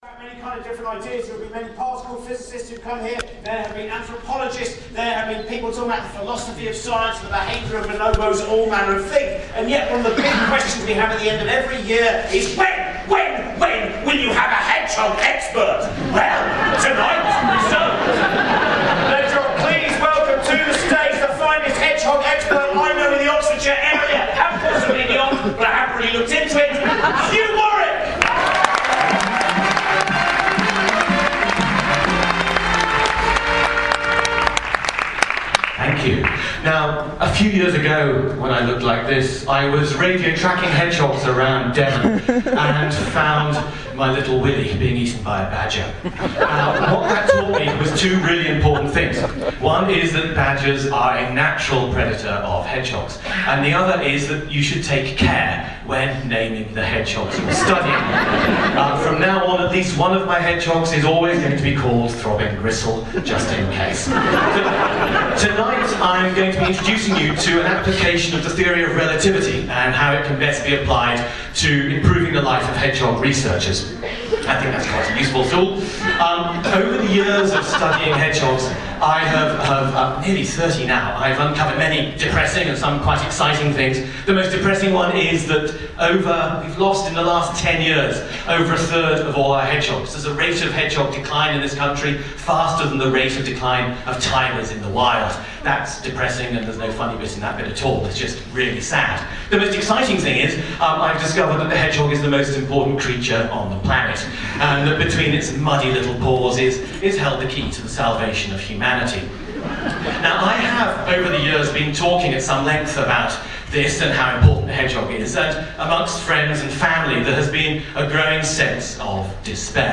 The day came – I was so scared – there were people I had heard on the radio sitting nonchalantly in the Bloomsbury Theatre’s Green Room – all chatting as if it was the most normal thing in the world, to step onto a stage in front of 500 people and make them happy … I sat in a corner and trembled.
Nine-Hedgehogs.mp3